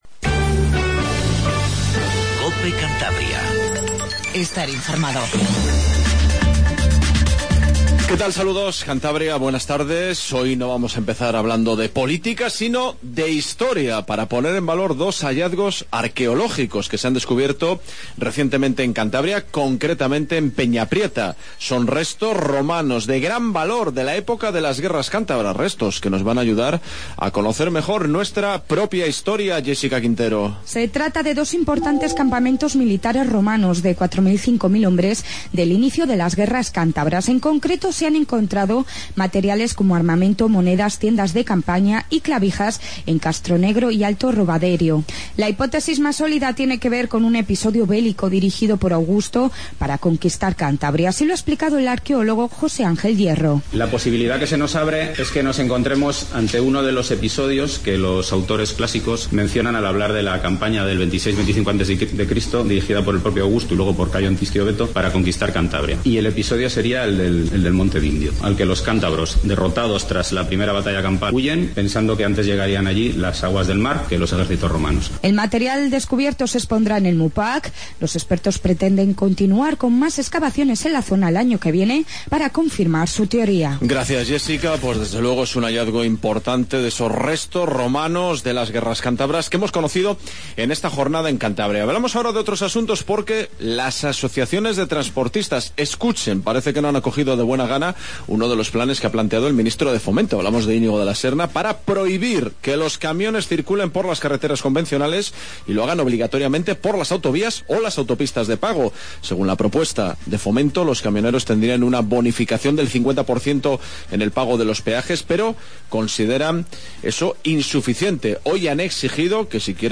AUDIO: Noticias